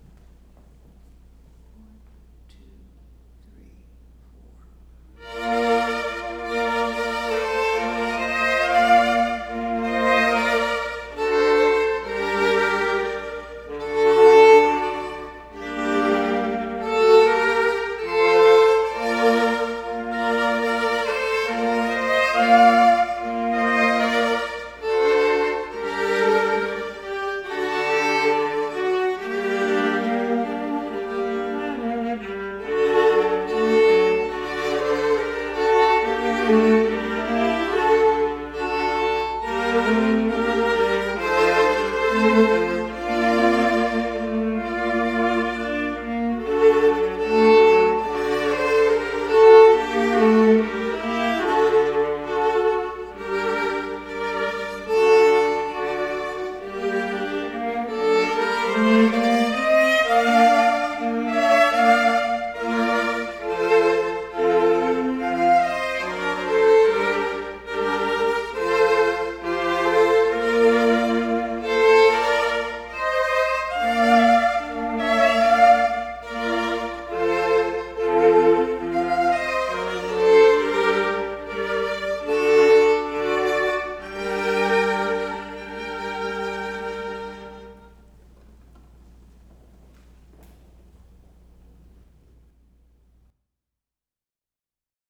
Ukrainian Anthem, recorded on Garage Band
violas